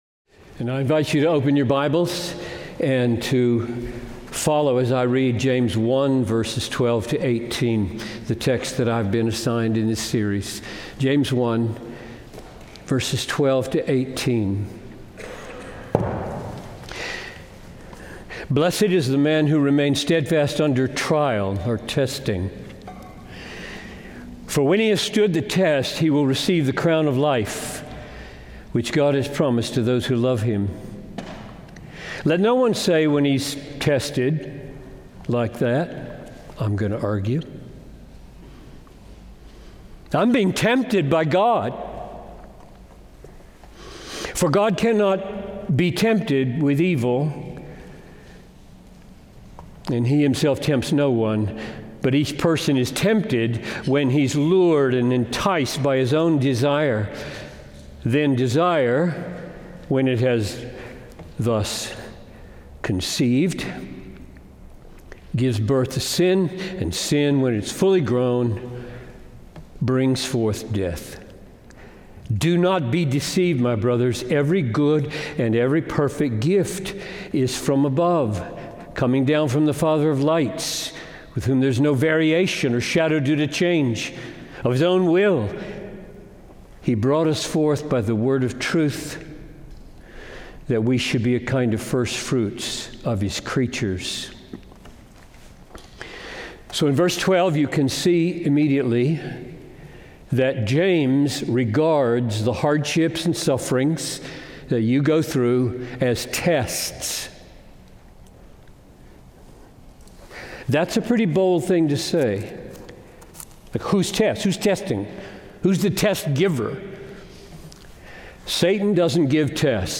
Bethlehem College and Seminary | Minneapolis